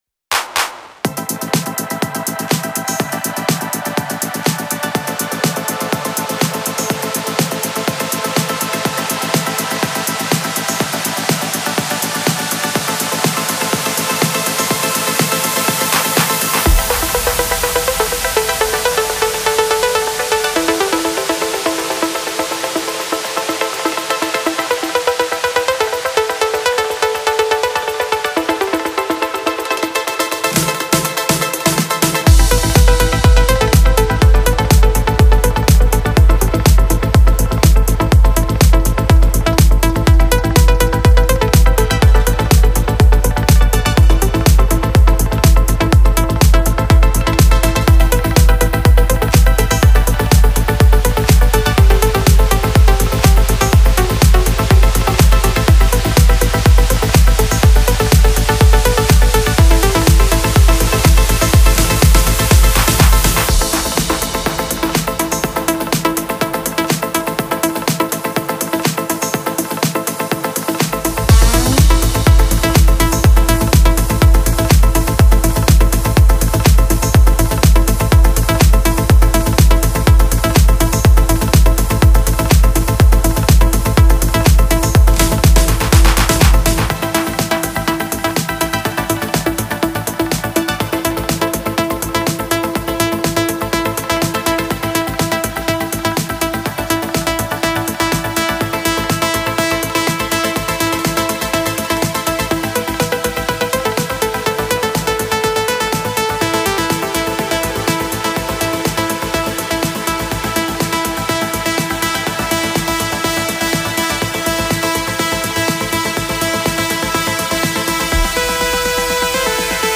موسیقی بی کلام هاوس
موسیقی بی کلام پر انرژی